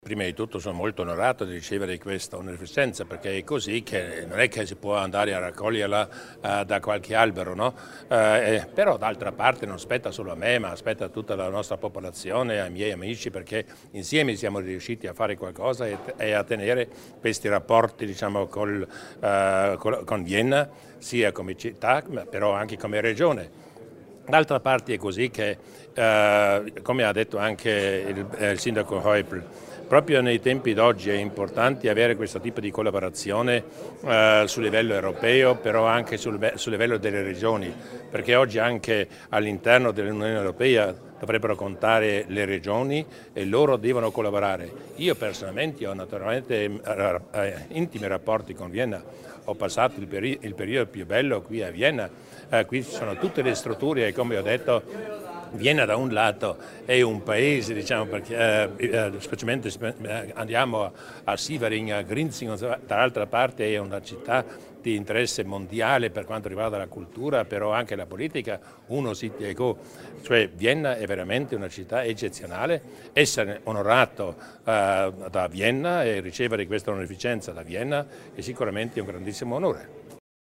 Il Presidente Durnwalder ringrazia per l'onorificenza ricevuta dalla città di Vienna